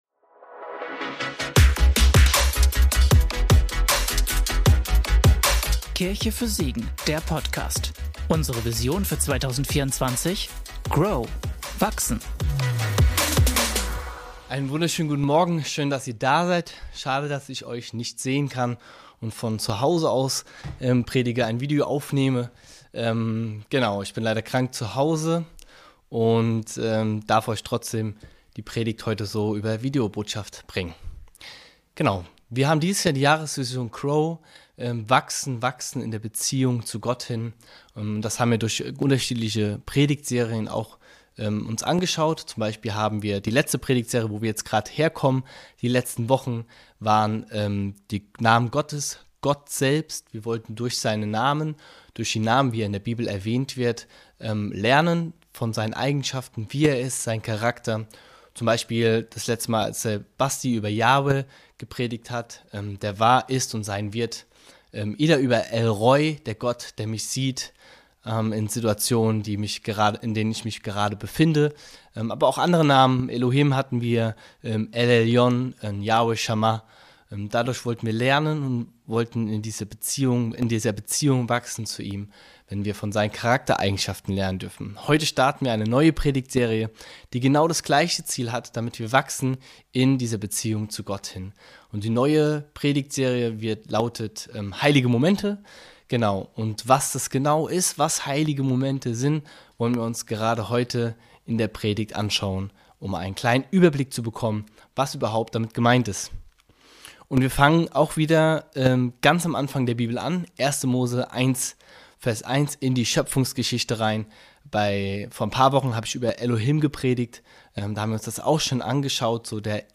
Heilige Momente - Predigtpodcast